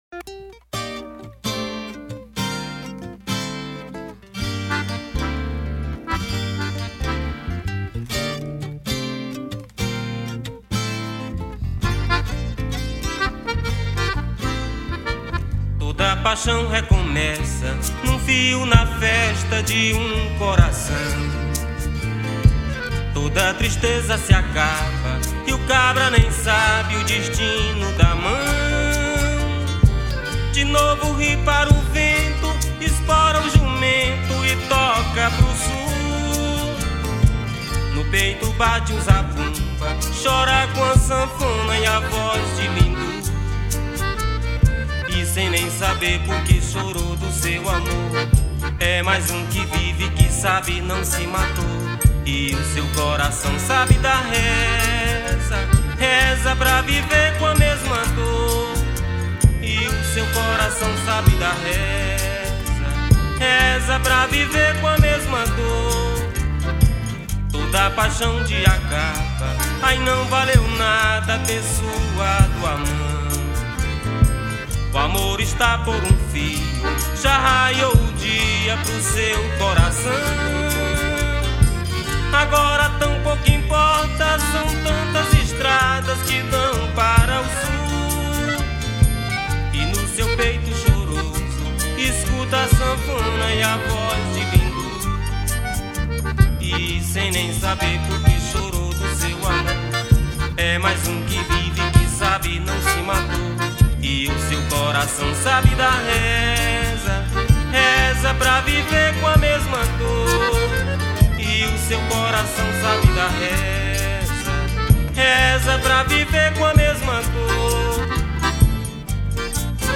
Baixo Elétrico 6, Violao Acústico 6
Percussão
Acoordeon
Viola